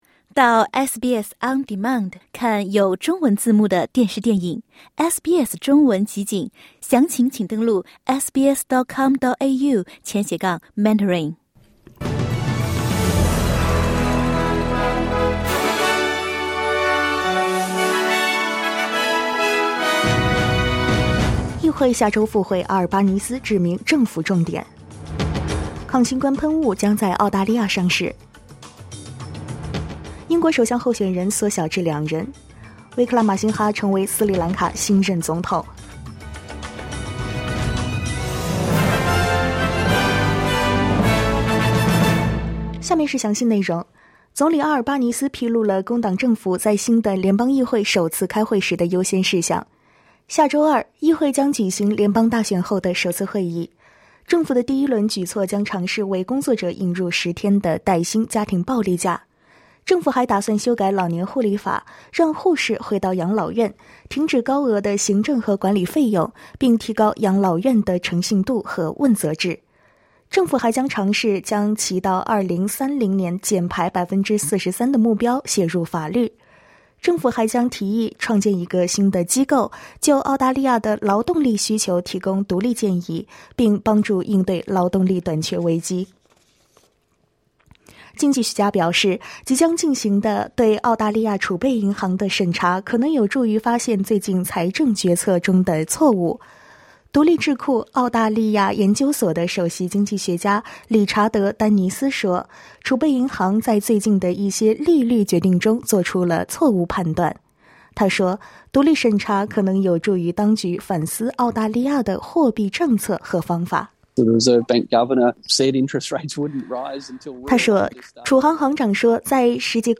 SBS早新闻（7月21日）